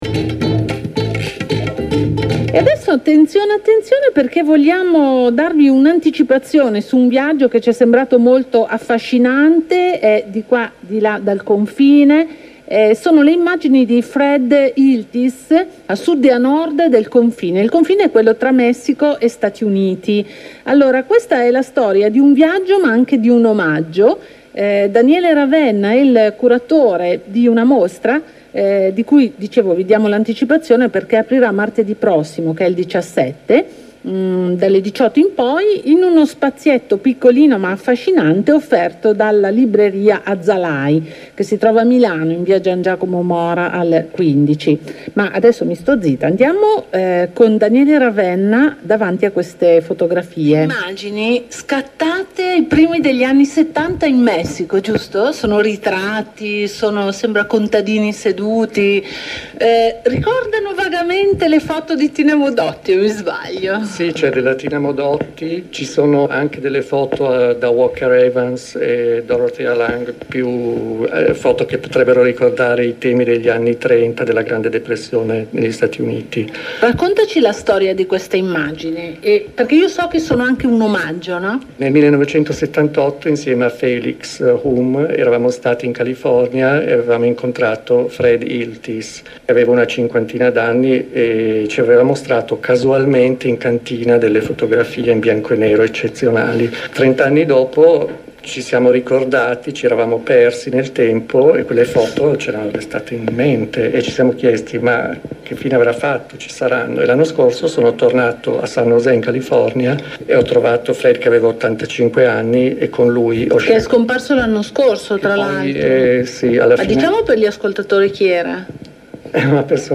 Il reportage di Radio Popolare in MP3 Recupero dati da hard disk